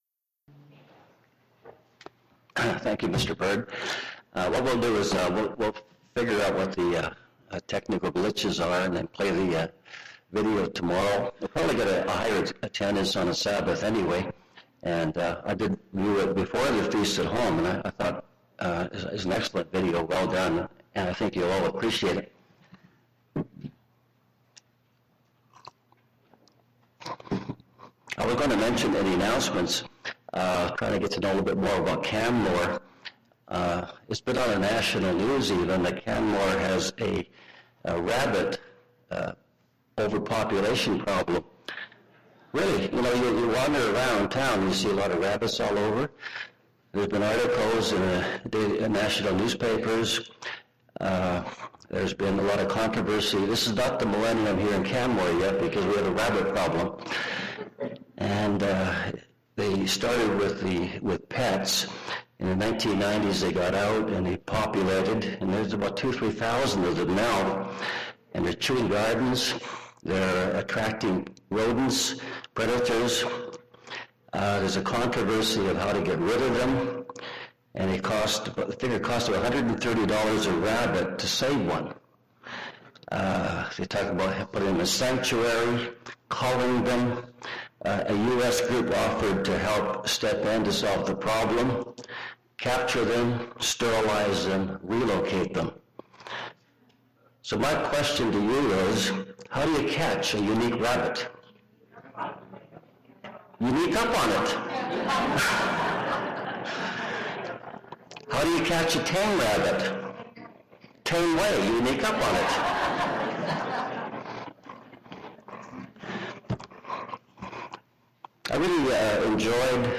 This sermon was given at the Canmore, Alberta 2012 Feast site.